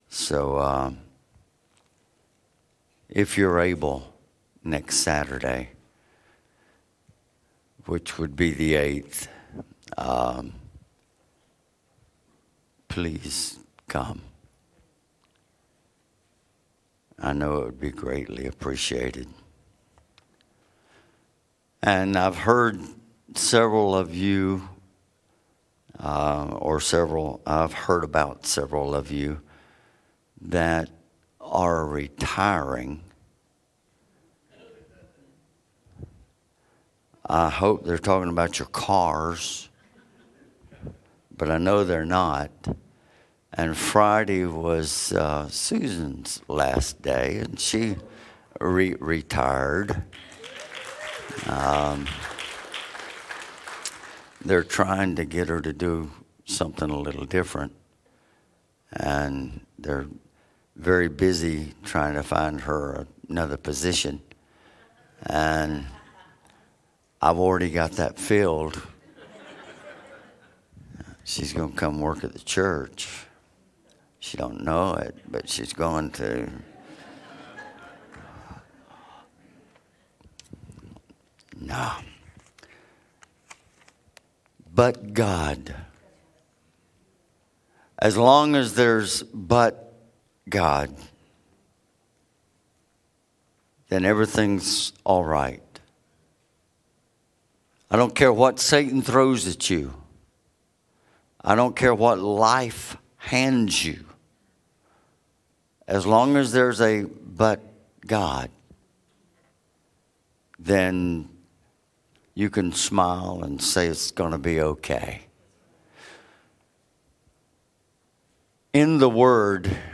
Sermons | First Assembly of God Rock Hill